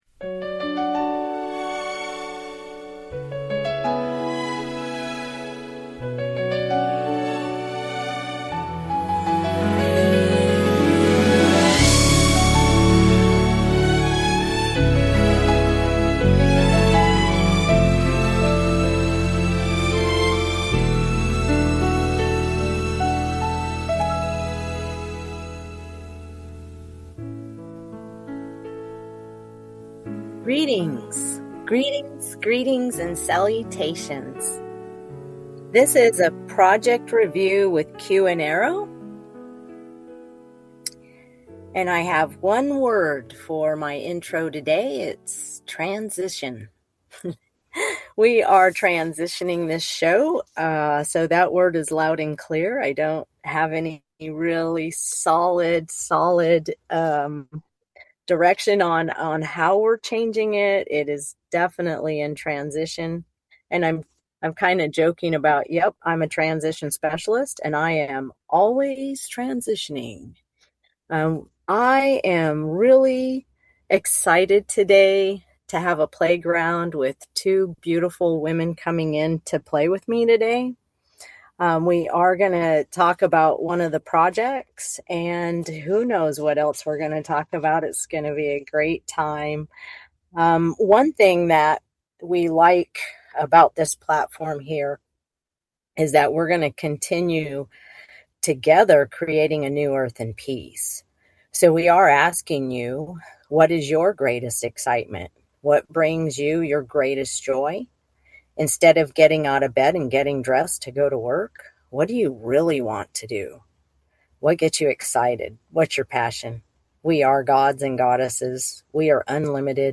Talk Show Episode, Audio Podcast, Project Review with Q n A and Transitioning to Heart-Centered Community: Project Review and Collaboration on , show guests , about Transitioning to Heart-Centered Community,Project Review and Collaboration,The Co-Creation Transition,Building a New Earth through heart-centered collaboration and local action,Homeless Outreach,The Vision of Collaboration Celebration,Collaboration Philosophy,From Digital Connection to Physical Presence,Practical Models for Local Outreach,Lessons in Service and Boundaries, categorized as Business,Education,Health & Lifestyle,Love & Relationships,Philosophy,Self Help,Society and Culture,Spiritual